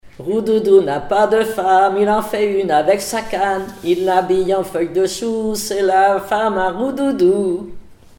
L'enfance - Enfantines - rondes et jeux
Comptines et formulettes enfantines
Pièce musicale inédite